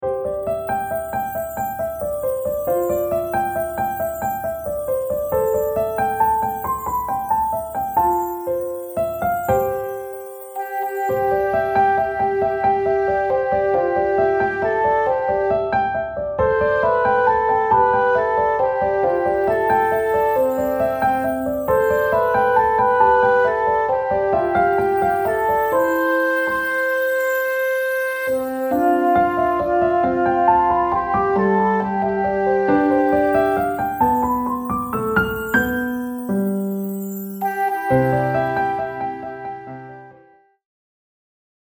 Genre : Australian lyrical song.